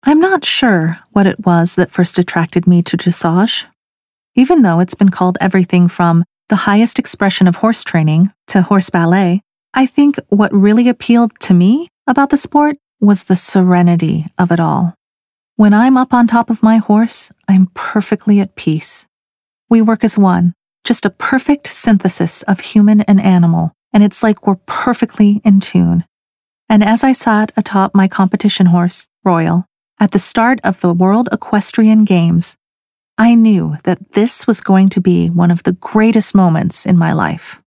Audiobook demo
Young Adult
Middle Aged